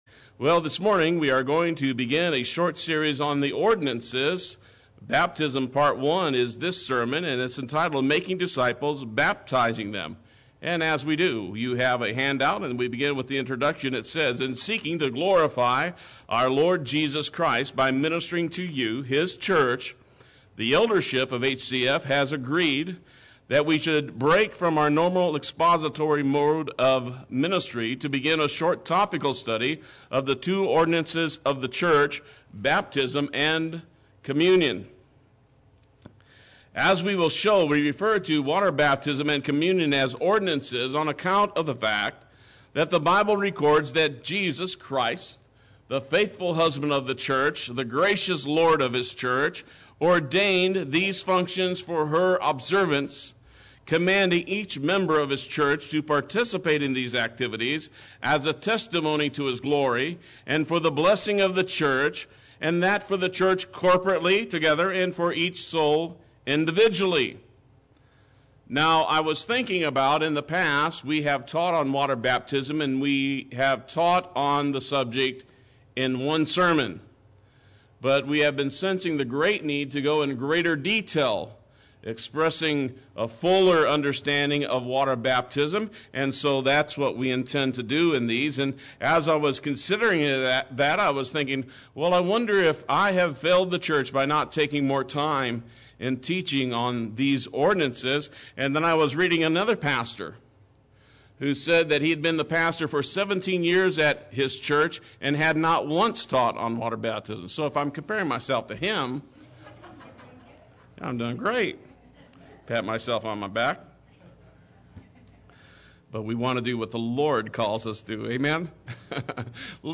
Play Sermon Get HCF Teaching Automatically.
Making Disciples and Baptizing Them Sunday Worship